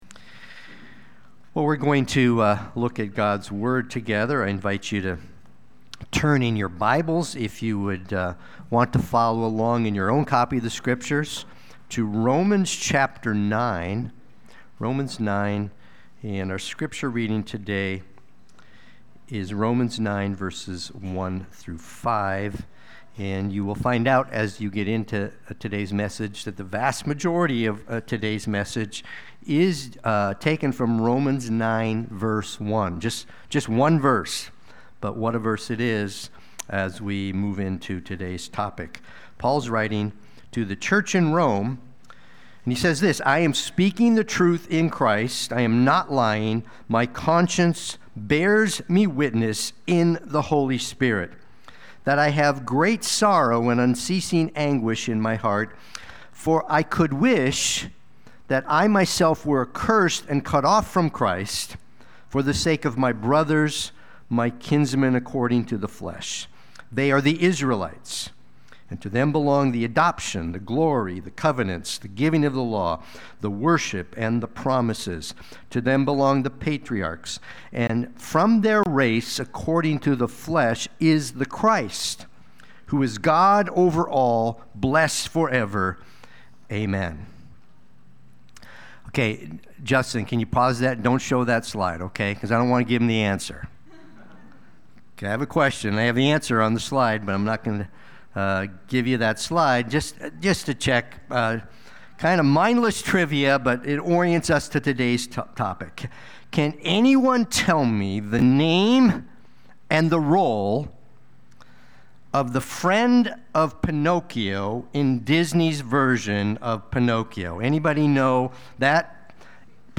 Sunday-Worship-main-81025.mp3